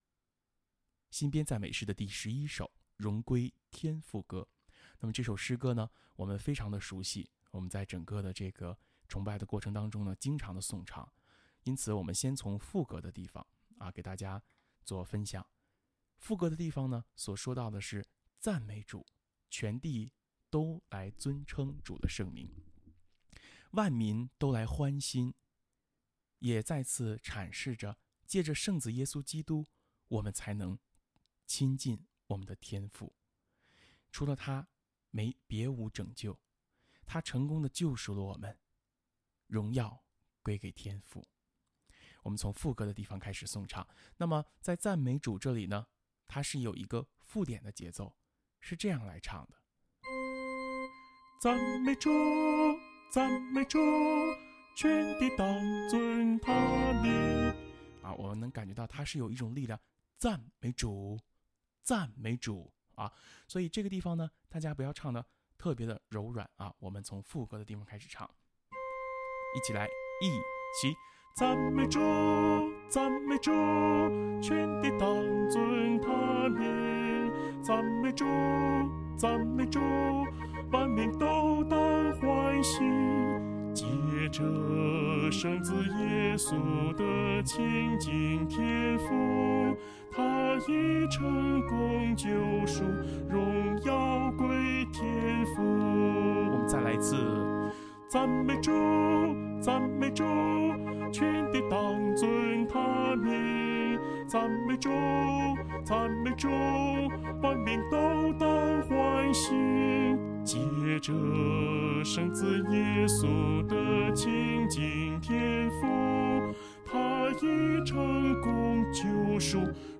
一、新编赞美诗11首 《荣归天父歌》